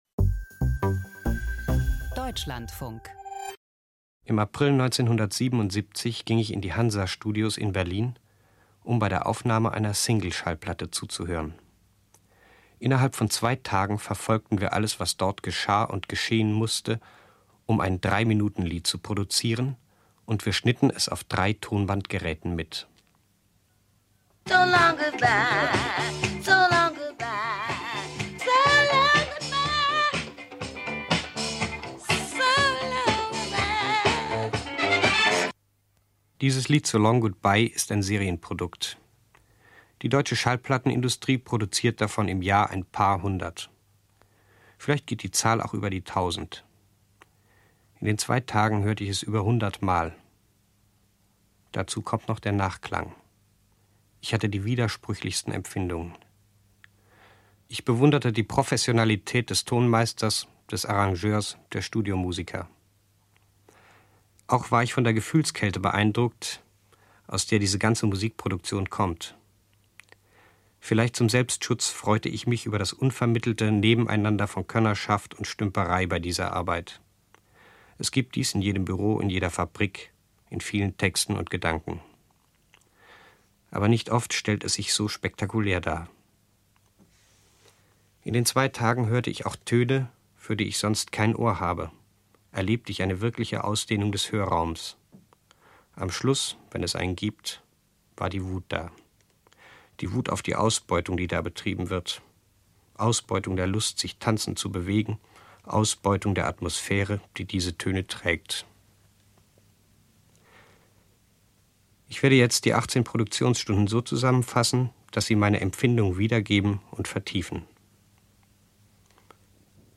Berlin 1978: in den Hansa-Tonstudios wird eine Disco-Single produziert. Dokumentarfilmer Harun Farocki, damals für kurze Zeit im Radio tätig, ist von Anfang bis Ende dabei und seziert den Aufnahmeprozess mit kapitalismuskritischem Besteck.